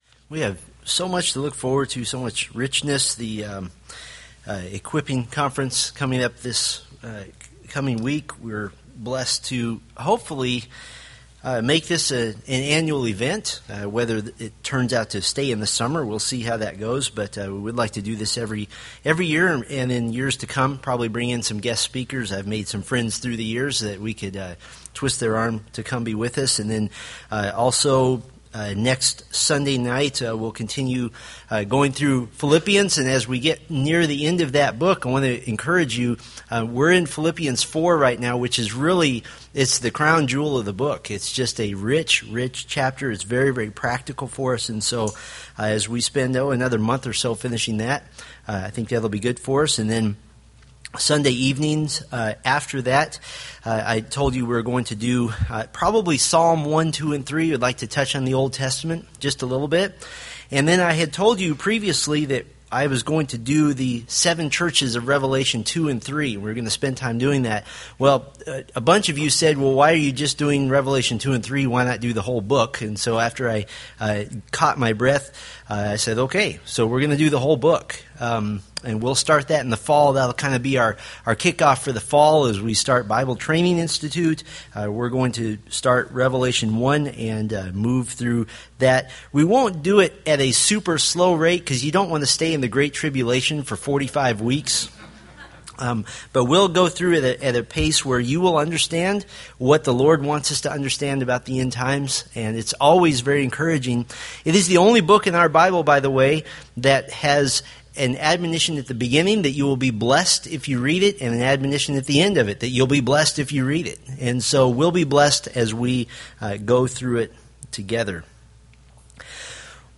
Preached July 21, 2013 from Selected Scriptures